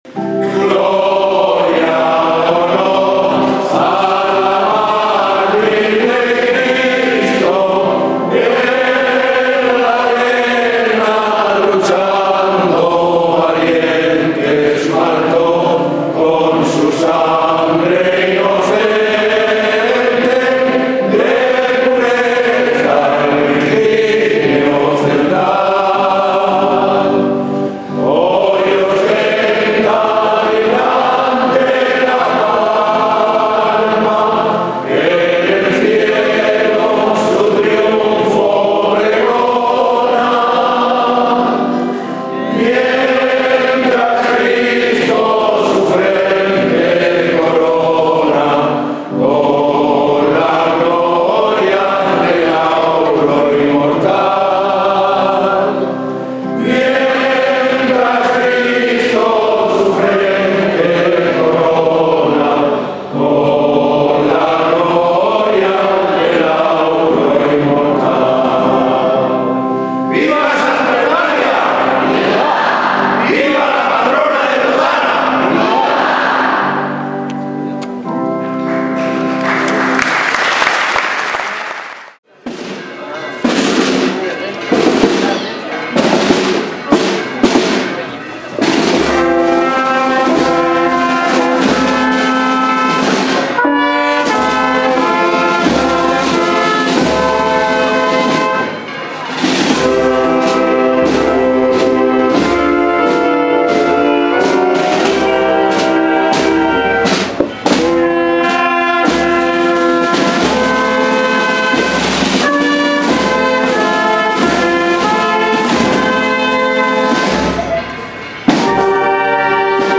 Misa en honor a Santa Eulalia y procesión - Totana 2013
Por la tarde, tuvo lugar la solemne procesión celebrada por las calles más céntricas de la ciudad, y con la que finalizan los principales actos religiosos de las fiestas patronales que se han celebrado durante los últimos días en esta localidad.
La Banda de Música de la Agrupación Musical de Totana cerró la comitiva.